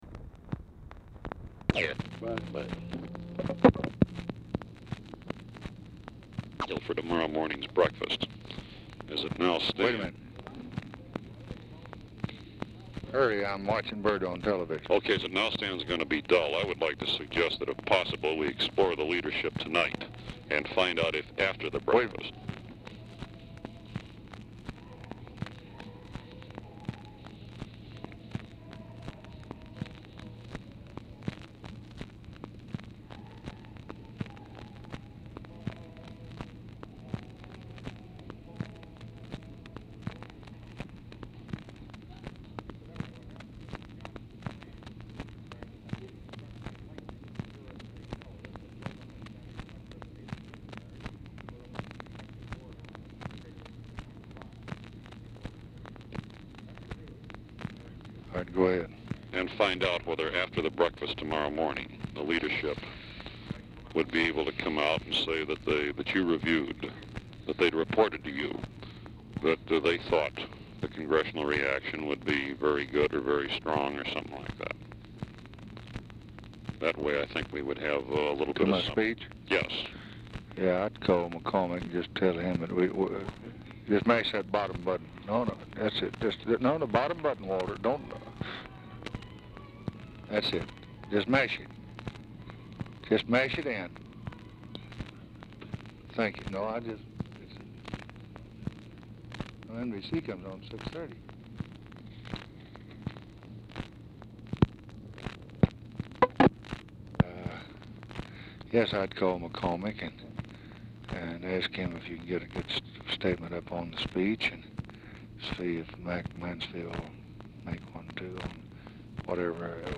Telephone conversation # 3070, sound recording, LBJ and GEORGE REEDY, 4/20/1964, 7:29PM | Discover LBJ
LBJ INTERRUPTS REEDY, PAUSES TO WATCH LADY BIRD JOHNSON ON TV, THEN TALKS TO WALTER JENKINS WHO IS WITH LBJ AT TIME OF CALL
Format Dictation belt
Specific Item Type Telephone conversation